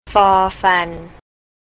ฟอ-ฟัน
for fan
fan (low tone).